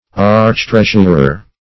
Search Result for " archtreasurer" : The Collaborative International Dictionary of English v.0.48: Archtreasurer \Arch`treas"ur*er\ (?; 135), n. [Pref. arch- + treasurer.] A chief treasurer.